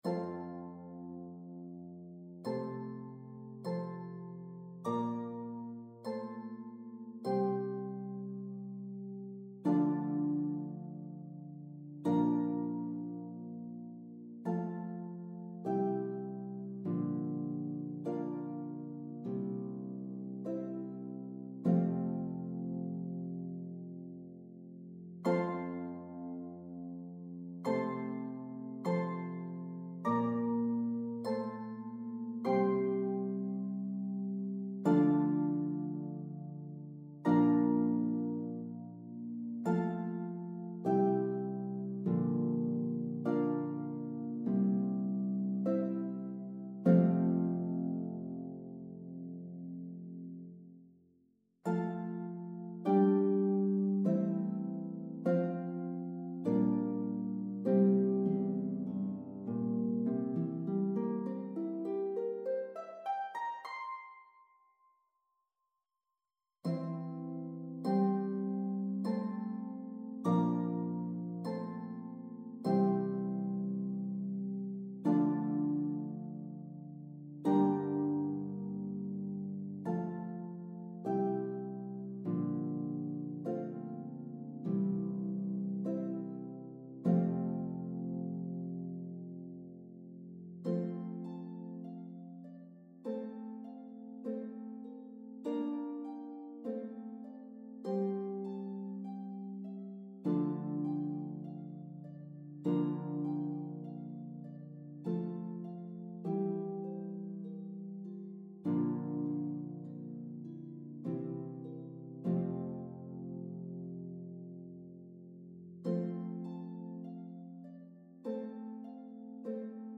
This is a beloved Advent Hymn from the Renaissance period.